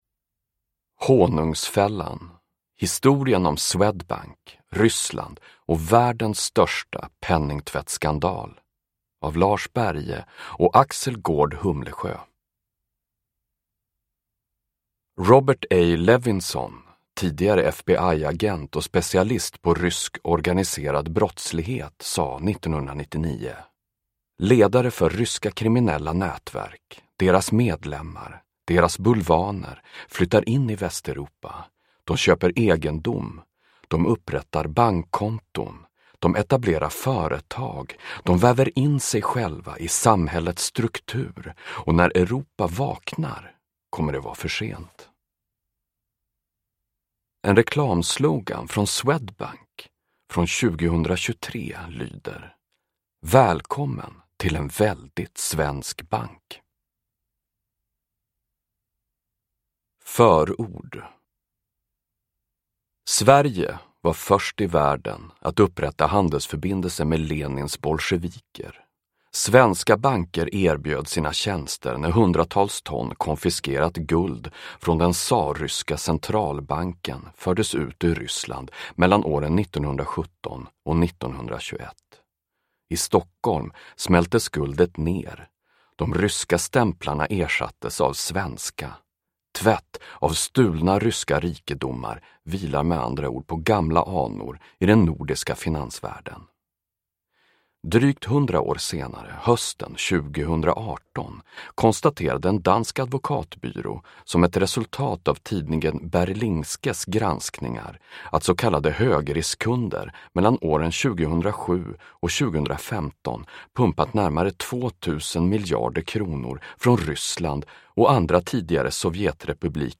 Honungsfällan : historien om Swedbank, Ryssland och världens största penningtvättskandal (ljudbok) av Lars Berge